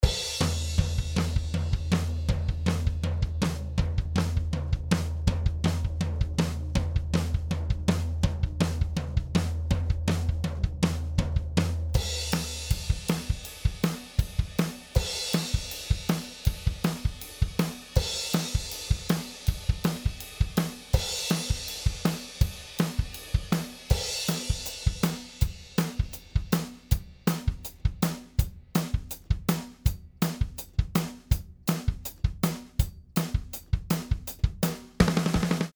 B172A 2A Drums (Limiter)
b172a-2a-drums-lim.mp3